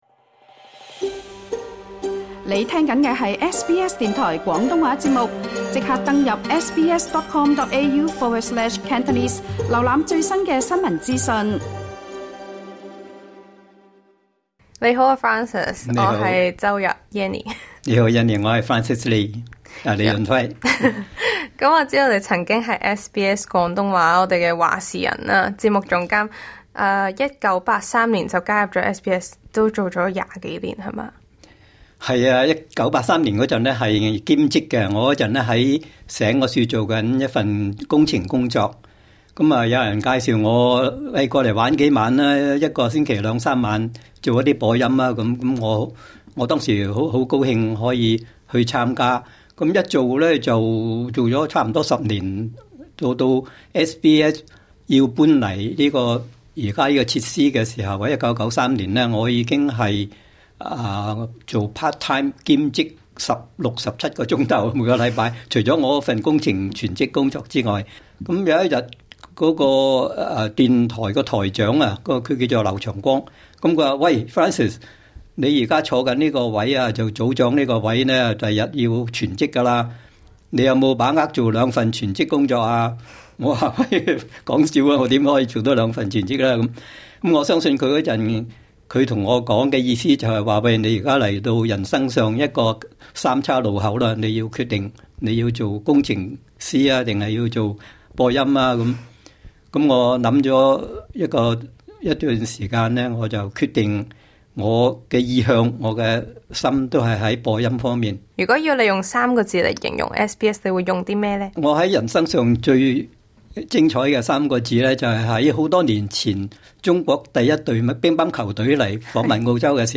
請收聽這一節 SBS 50 周年專訪。